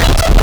Player_UI [106].wav